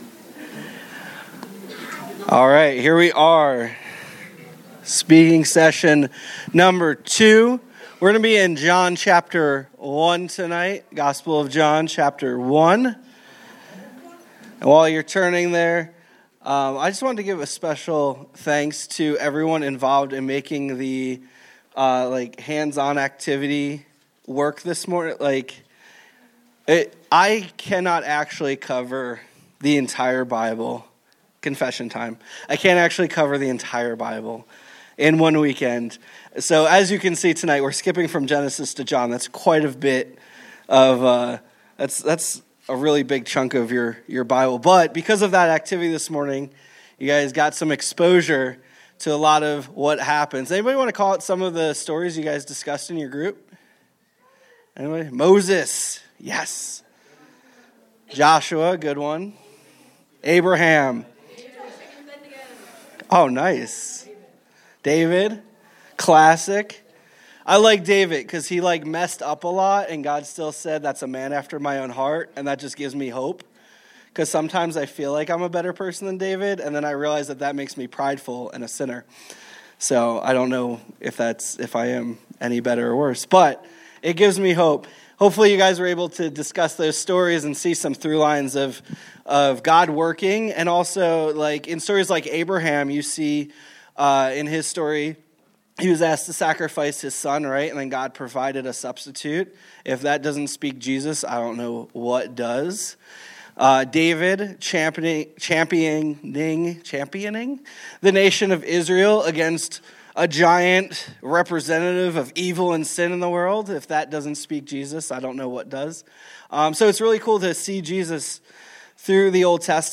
Saturday evening message at THAW - March 8, 2025. Part 2 of the Overview Effect series focuses on truths from John 1:1-5.